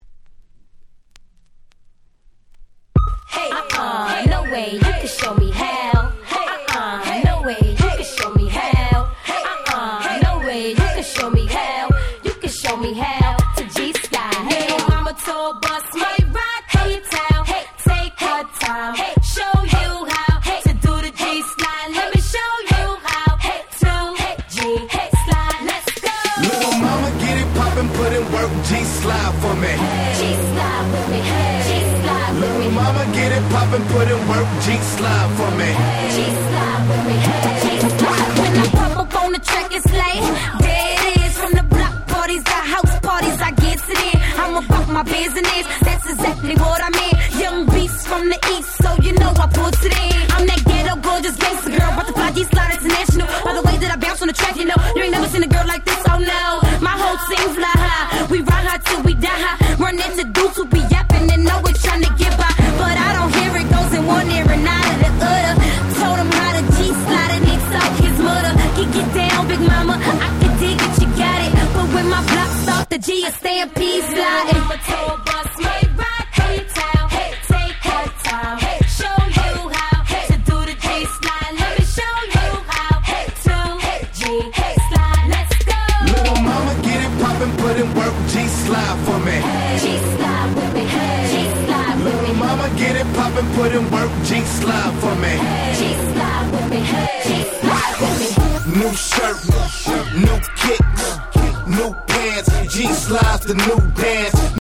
07' Smash Hit Hip Hop !!
流行りのSouth Beatを乗りこなし、当時はここ日本のClubでもよくPlayされていた1曲です。